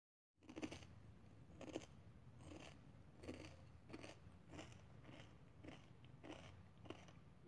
Tag: 环境 atmophere 记录